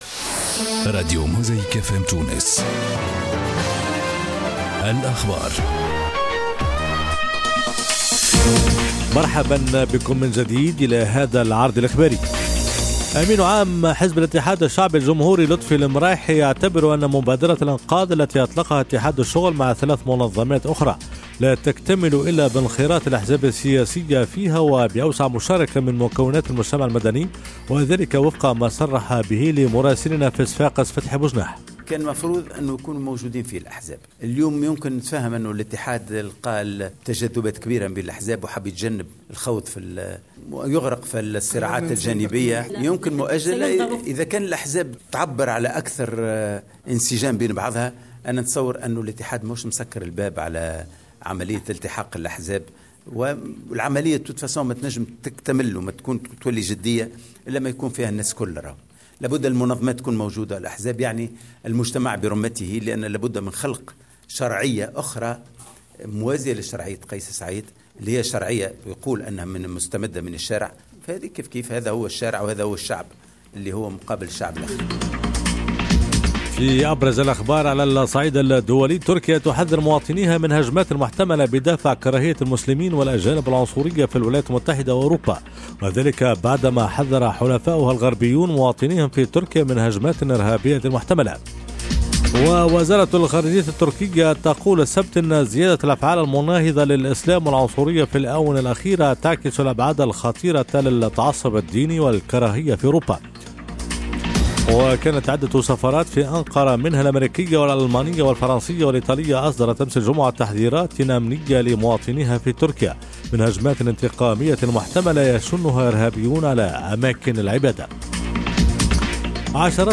نشرات أخبار جانفي 2023